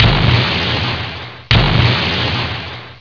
depth charges bombardement, we have all our tubes reloaded.